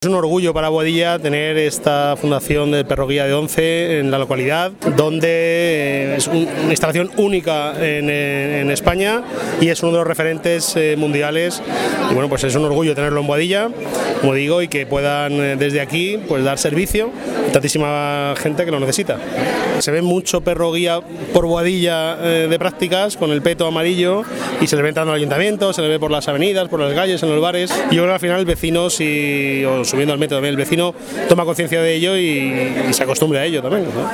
"un orgullo para la localidad", tal y como aseguraba su alcalde tras la inauguración, y para todos los ciudadanos que conviven cada día en sus calles con los perros que se están educando.
alcalde-boadilla-mp3